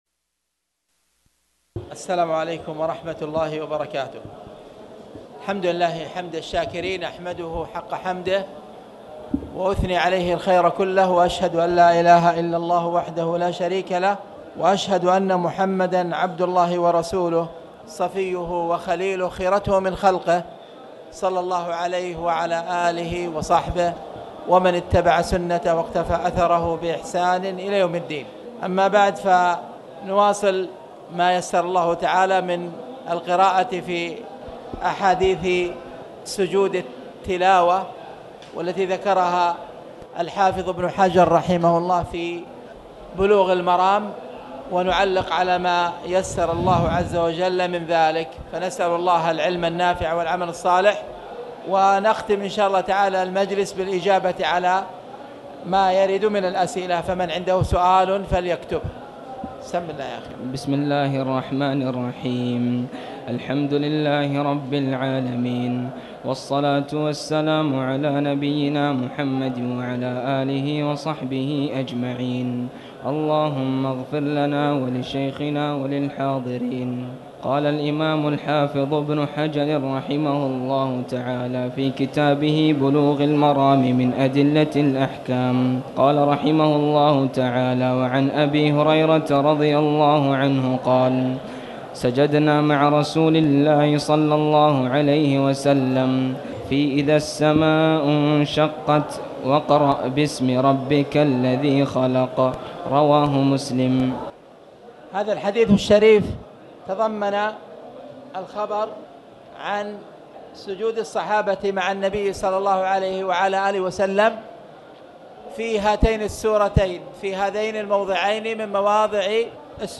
تاريخ النشر ٣ ربيع الثاني ١٤٣٩ هـ المكان: المسجد الحرام الشيخ